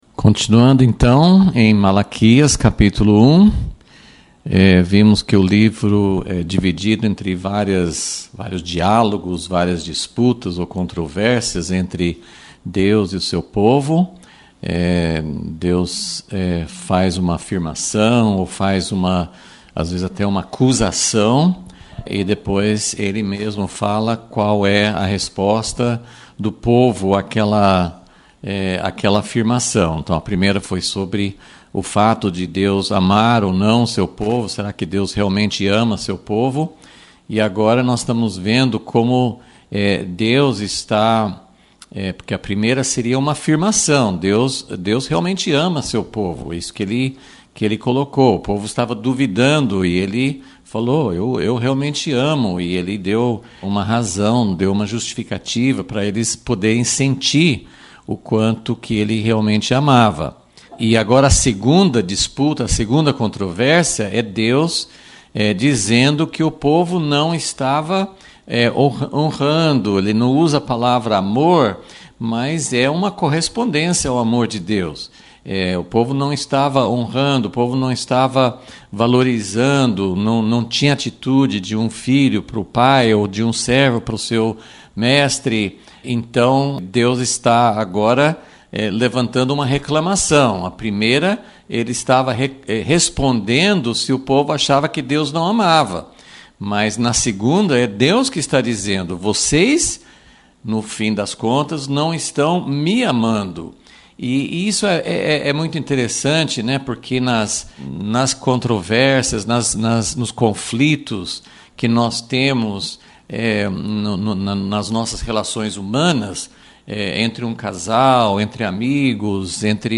Aula 5 – Vol.37 – Será que as Nações darão a Deus adoração mais pura que Israel?